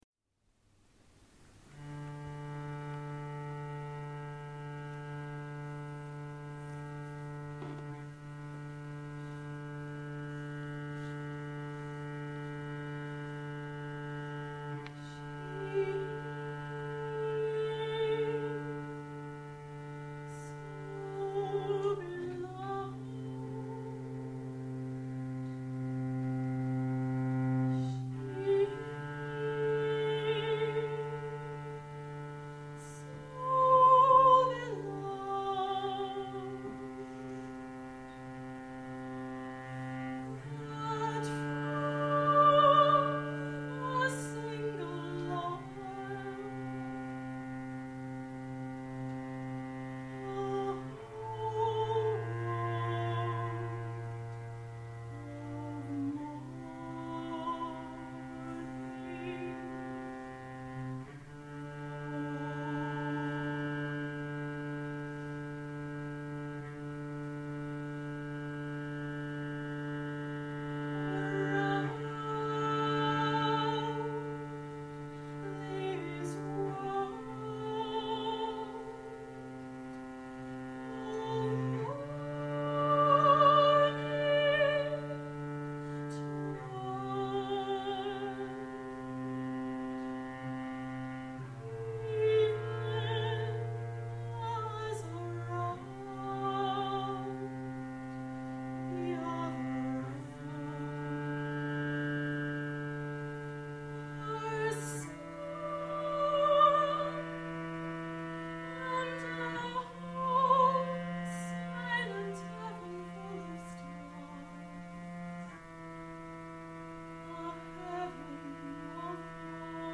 Voice, violin & cello
Contemporary improvisation live from the Boite World Music Cafe – 2008
This is the only performance not fully improvised from a night of improvised music at the famous Boite World Music Cafe in Fitzroy Melbourne Australia.
voice & violin
Cello
voice-violin-cello-the-boite-july-2008.mp3